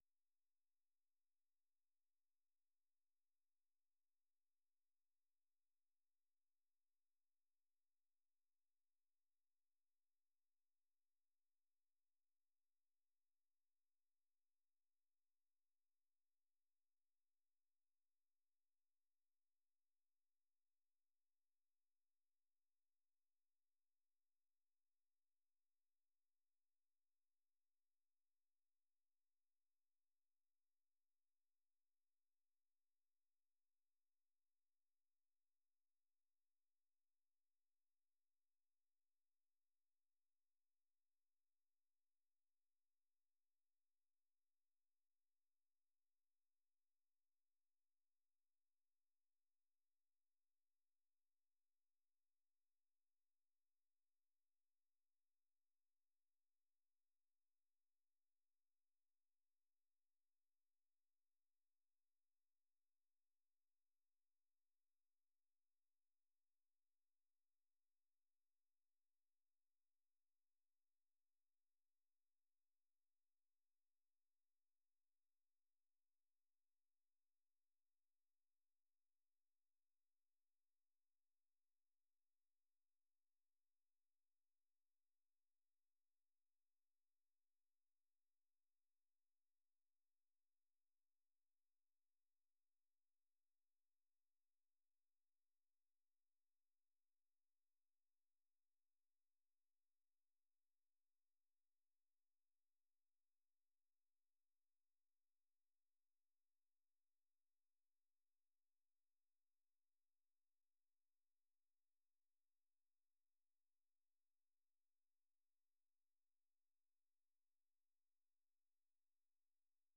ข่าวสดสายตรงจากวีโอเอ ภาคภาษาไทย 8:30–9:00 น.